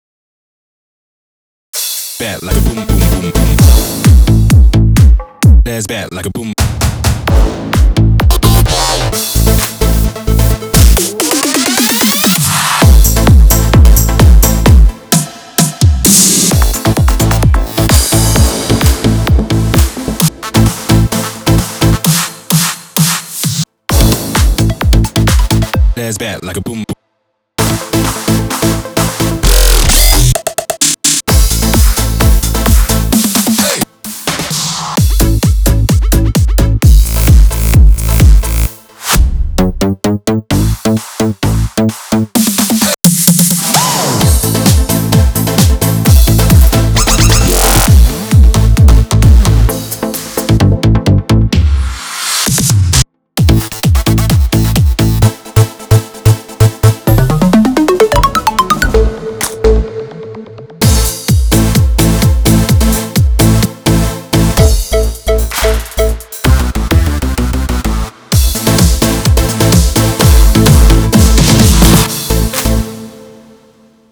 מי שיכול לומר של מי המקצבים האלה תודה